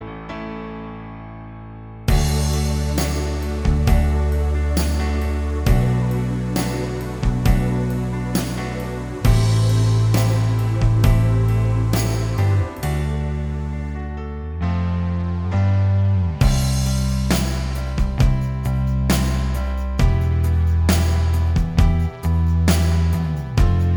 Minus Lead Guitar Pop (1980s) 4:01 Buy £1.50